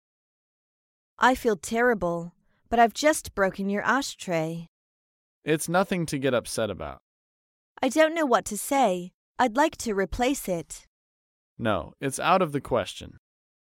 在线英语听力室高频英语口语对话 第116期:打破物品致歉的听力文件下载,《高频英语口语对话》栏目包含了日常生活中经常使用的英语情景对话，是学习英语口语，能够帮助英语爱好者在听英语对话的过程中，积累英语口语习语知识，提高英语听说水平，并通过栏目中的中英文字幕和音频MP3文件，提高英语语感。